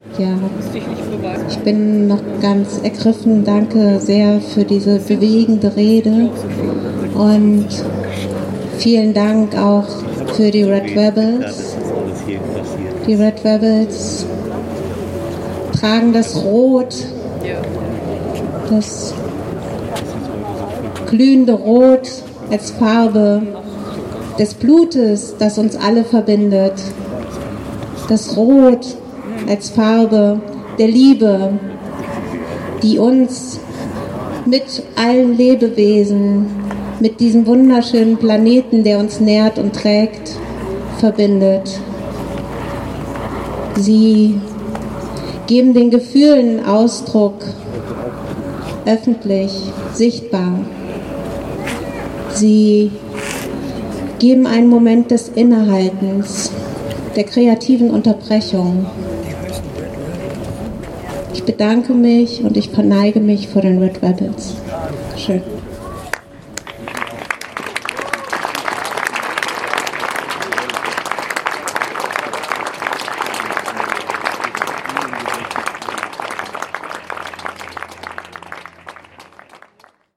Abschlusskundgebung
Die Dankrede